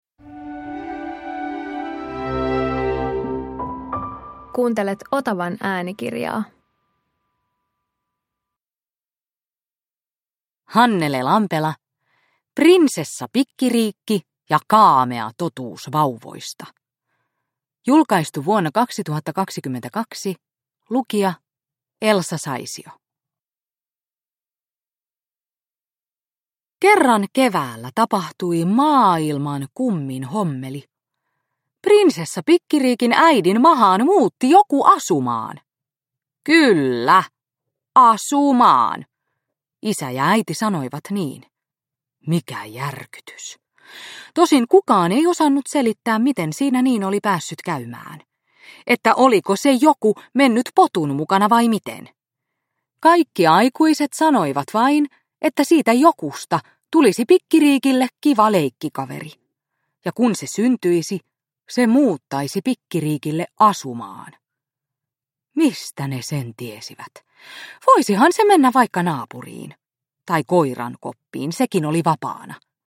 Prinsessa Pikkiriikki ja (kaamea) totuus vauvoista – Ljudbok – Laddas ner